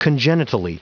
Prononciation du mot congenitally en anglais (fichier audio)
Prononciation du mot : congenitally